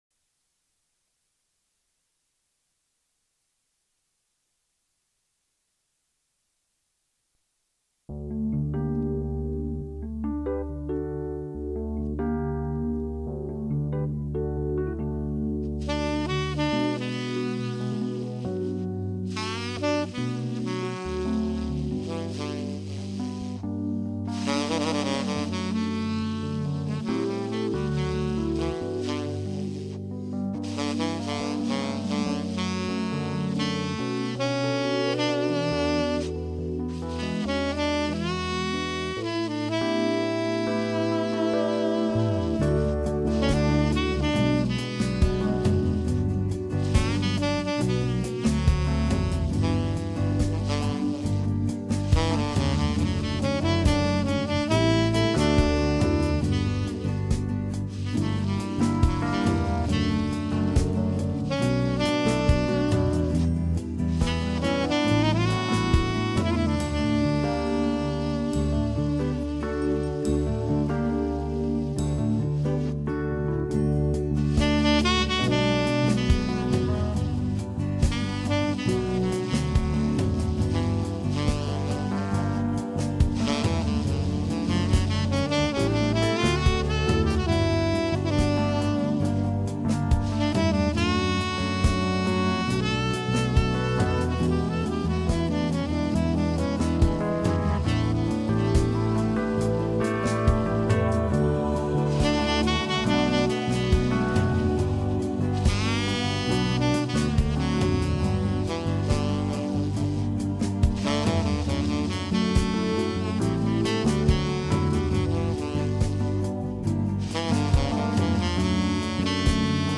Saxofonunderhållning